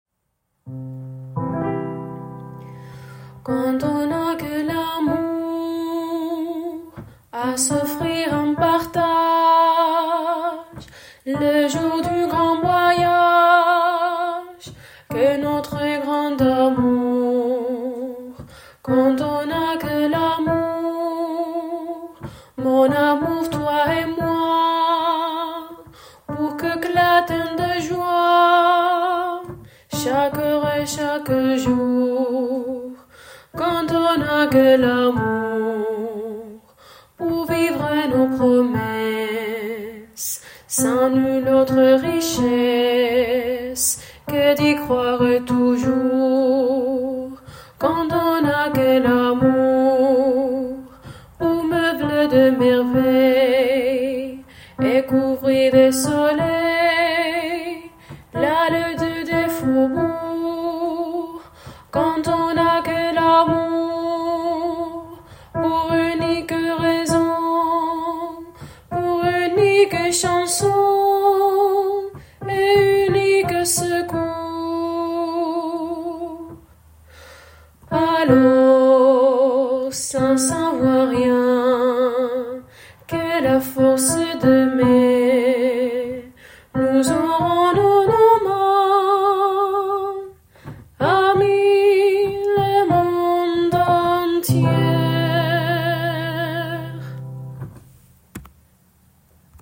Alti